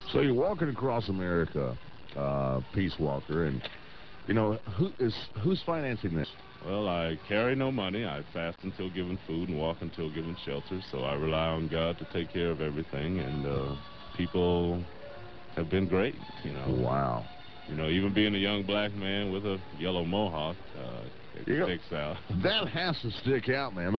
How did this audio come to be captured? at KYJT radio Yuma, AZ